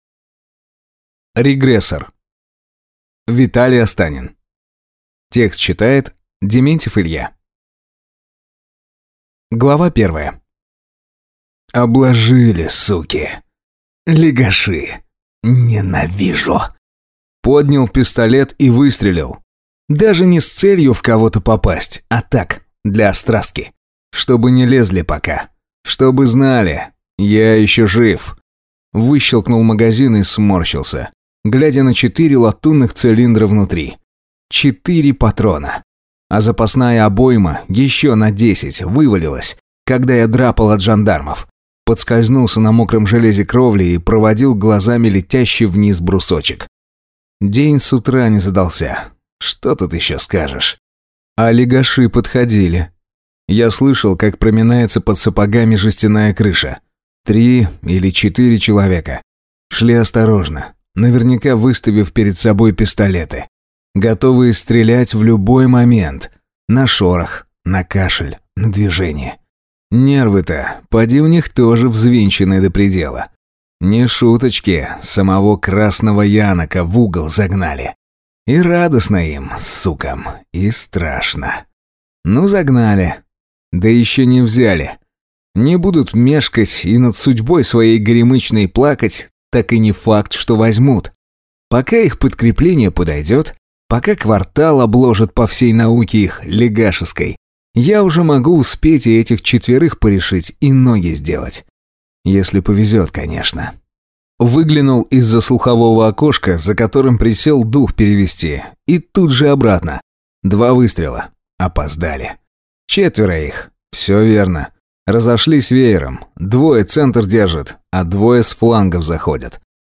Аудиокнига Регрессор | Библиотека аудиокниг